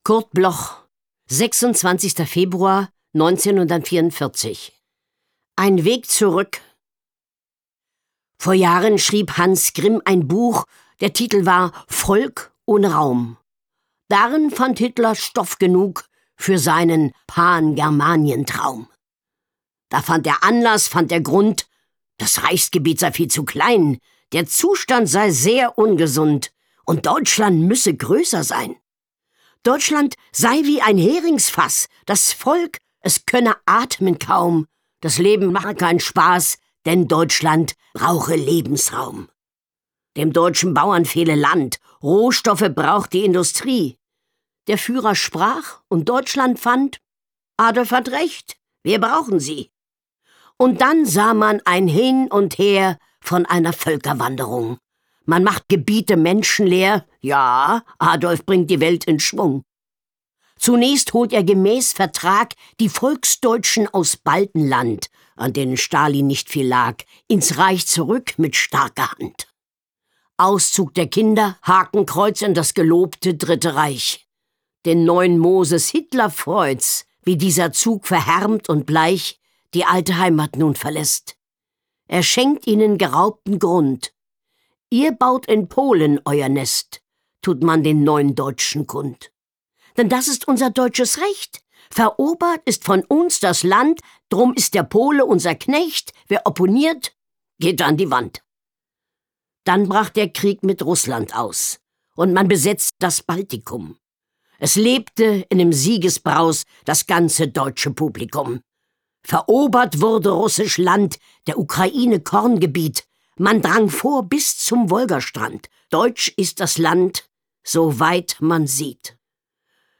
Carmen-Maja Antoni (* 1945) ist eine deutsche Schauspielerin und Hörspielsprecherin.
Aufnahme: Speak Low, Berlin · Bearbeitung: Kristen & Schmidt, Wiesbaden